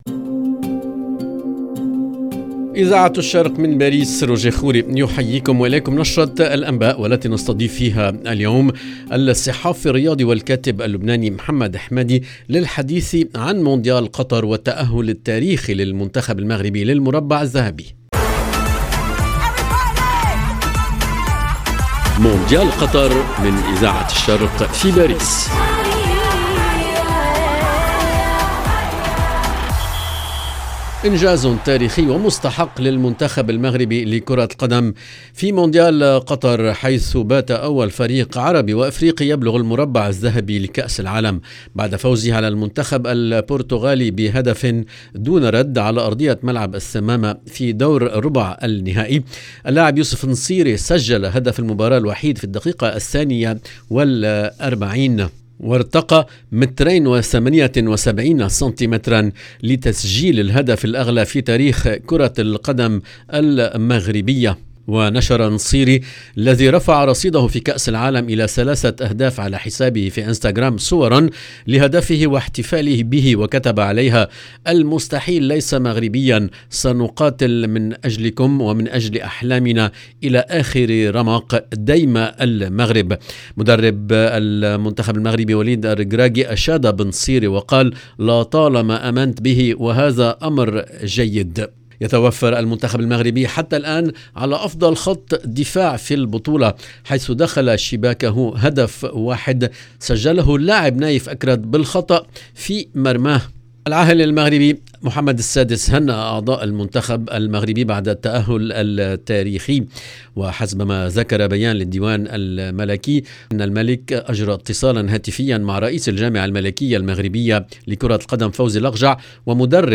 LE JOURNAL DU SOIR EN LANGUE ARABE DU 11/12/2022